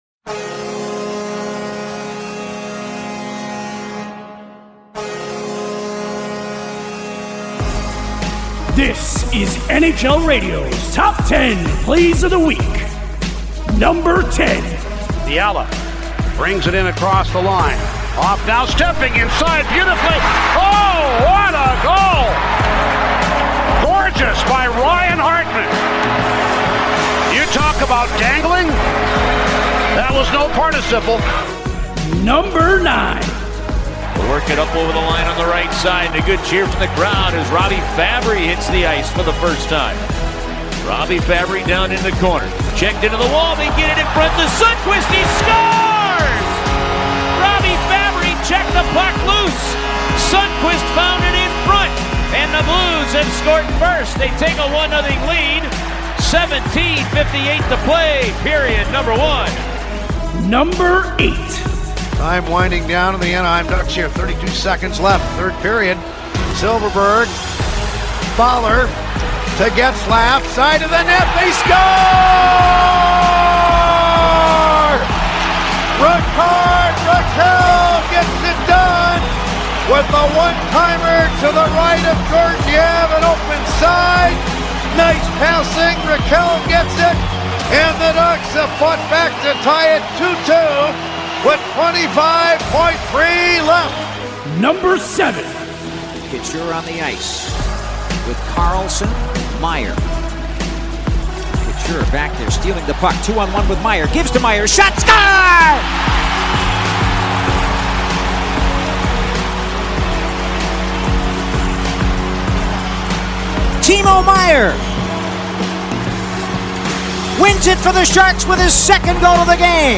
Week of Monday, October 29th: Your source for the top radio calls, plays and highlights from around the National Hockey League! The countdown begins…now!